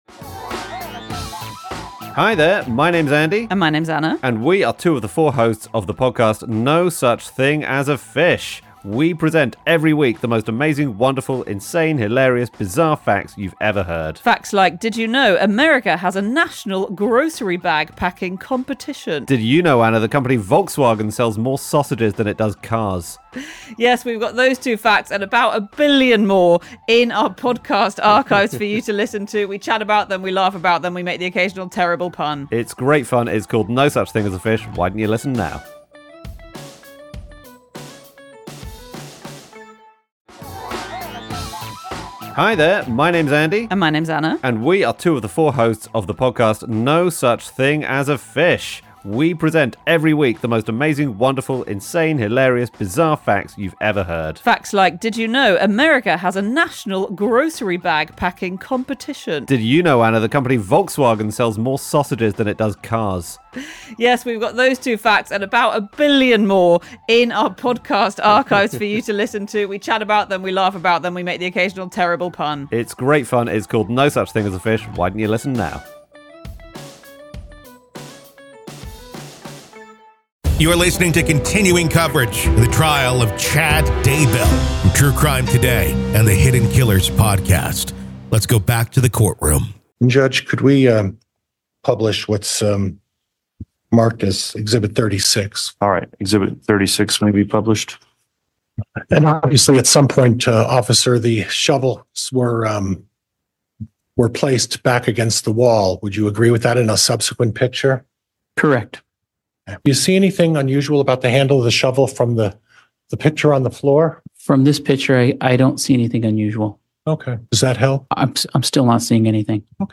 Full Courtroom Coverage